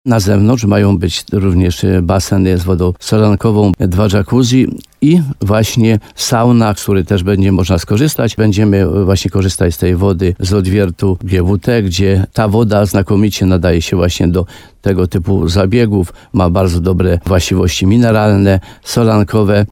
– Teraz prace trwają na tarasie – mówi starosta limanowski Mieczysław Uryga.